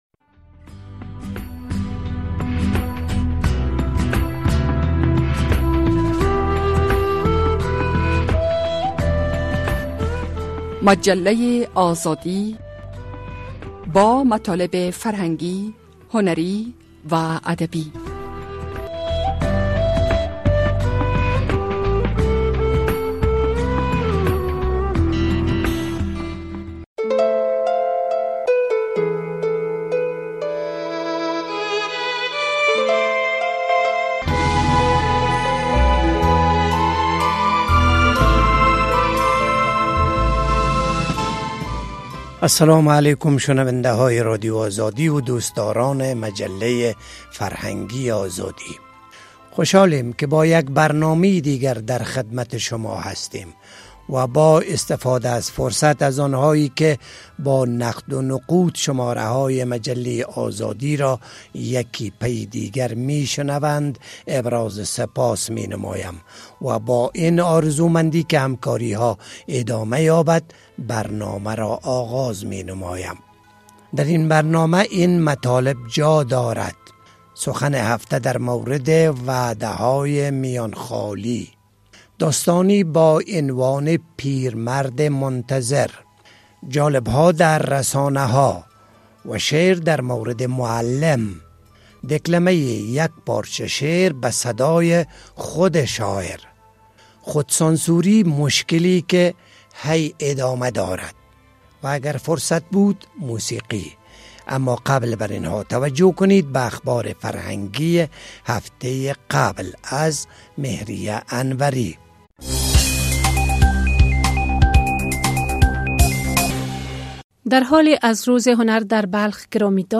درین شماره مجله آزادی داریم: سخن هفته در مورد وعده های میان خالی، داستانی با عنوان (پیرمرد منتظر) جالب ها در رسانه ها و شعر در مورد معلم، ‌دکلمه یک پارچه شعر به صدای شاعر، خود سانسوری مشکلی که ادامه دارد و آهنگ.